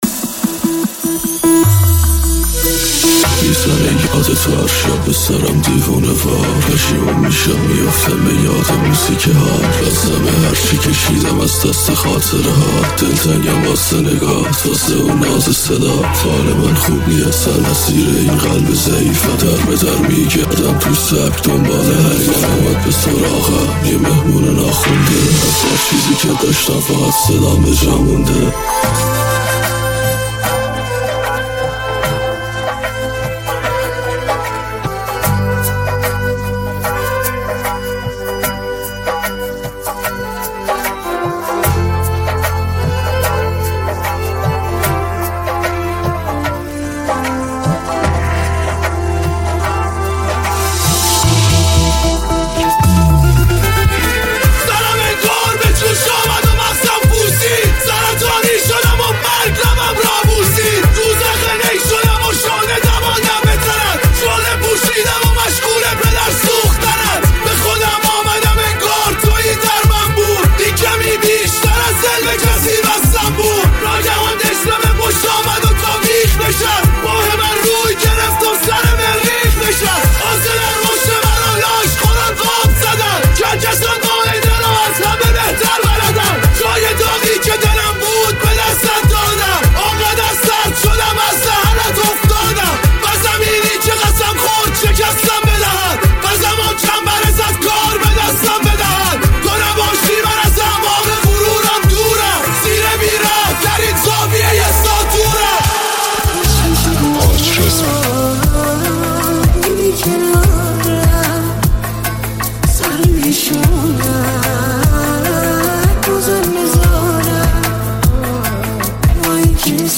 دیسلاو